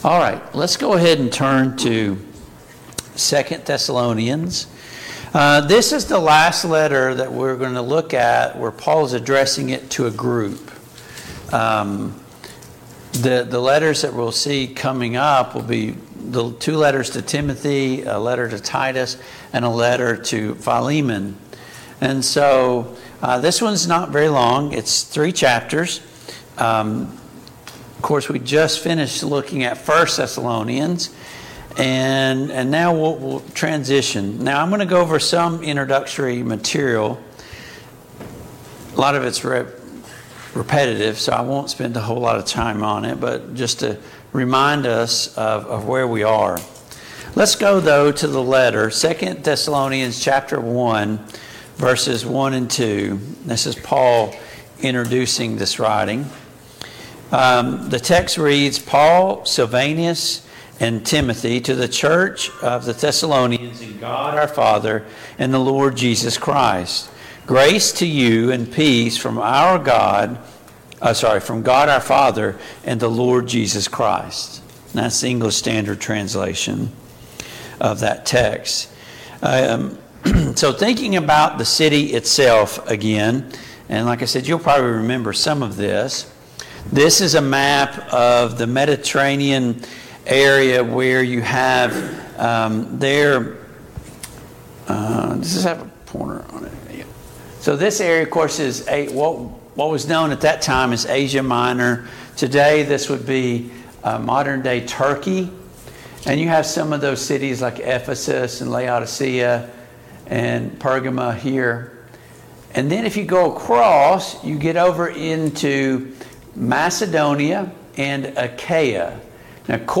Passage: 2 Thessalonians 1:1-7 Service Type: Mid-Week Bible Study